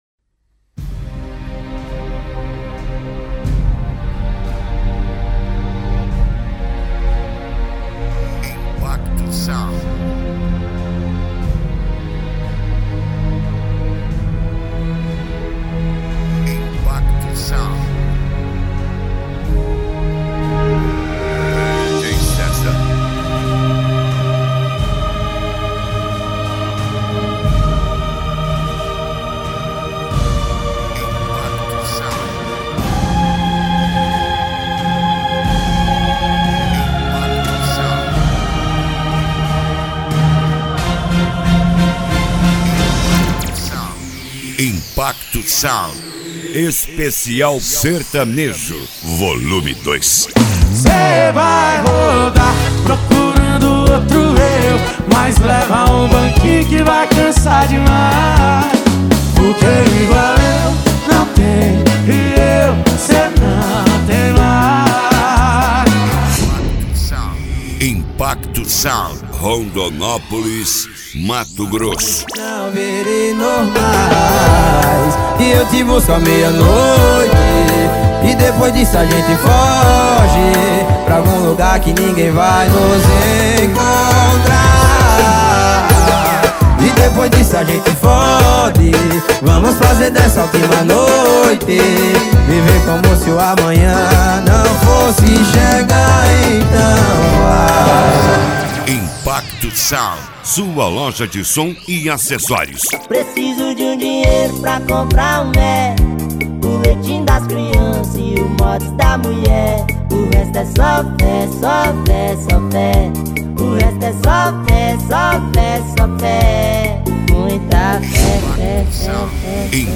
Modao
SERTANEJO
Sertanejo Raiz
Sertanejo Universitario